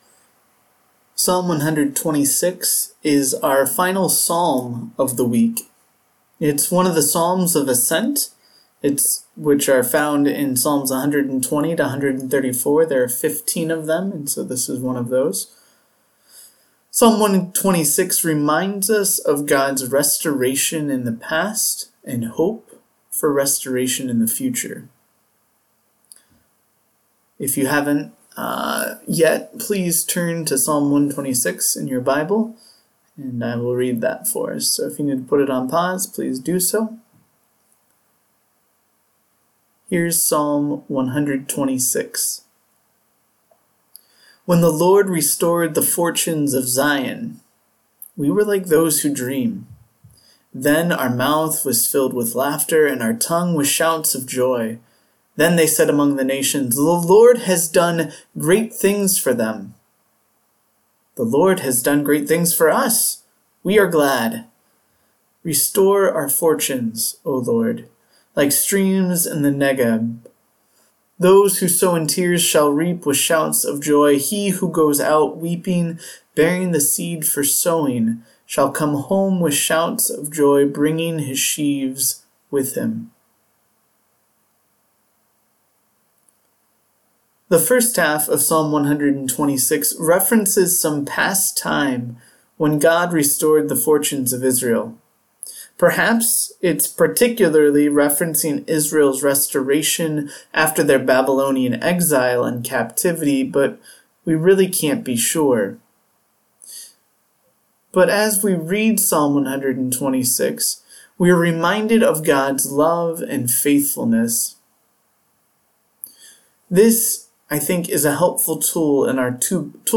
Our third devotional on the Psalms. This one from Psalm 126